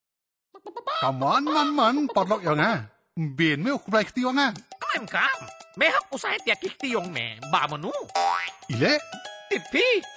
This is second in the series of five Radio PSA, address backyard farmers and their families. It uses a performer and a rooster puppet as a creative medium to alert families to poultry diseases and instill safe poultry behaviours.
Radio PSA